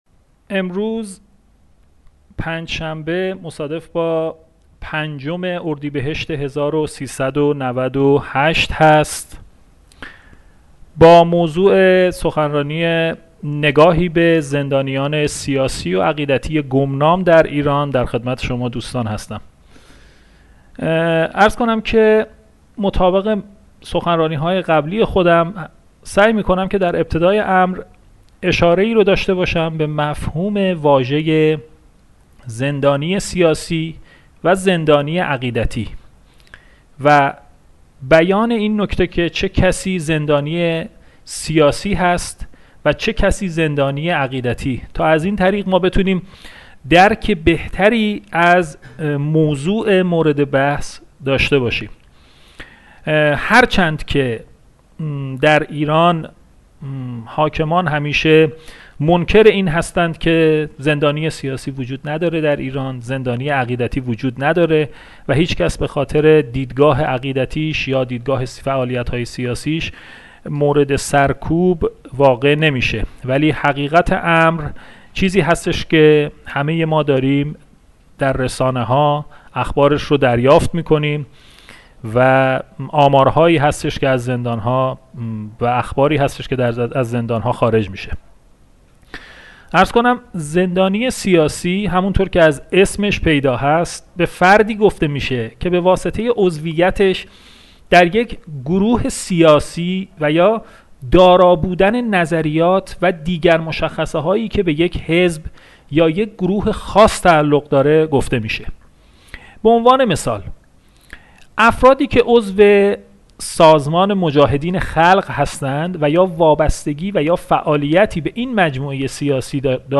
صحبتهای فعال حقوق بشر